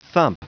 thump
thump.wav